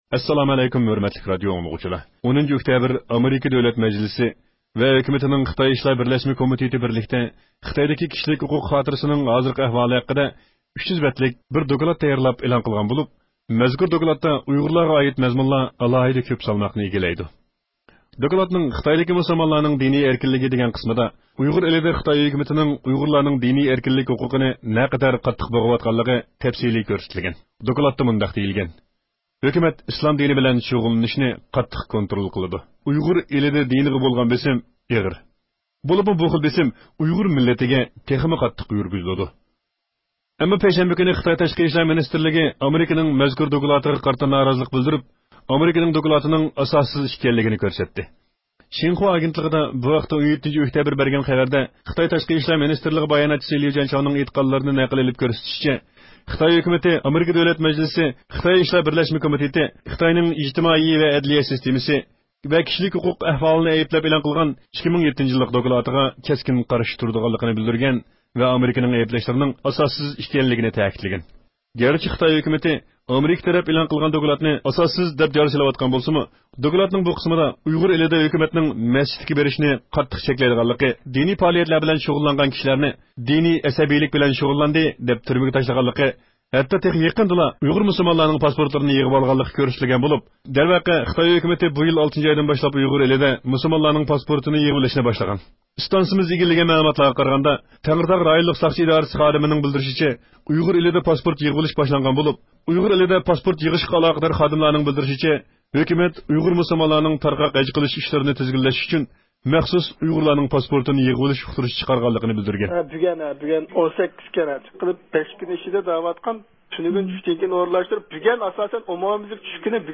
ھۆرمەتلىك رادىئو ئاڭلىغۇچىلار ، يۇقىرىدا سىلەر ئامېرىكا دۆلەت مەجلىسى ۋە ھۆكۈمىتىنىڭ خىتاي ئىشلىرى بىرلەشمە كومىتېتى خىتاينىڭ كىشىلىك ھوقۇق خاتىرىسىگە ئائىت تەييارلىغان 2007- يىللىق دوكلاتى ھەققىدە مەلۇمات ئاڭلىدىڭلار.